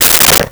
Telephone Receiver Down 03
Telephone Receiver Down 03.wav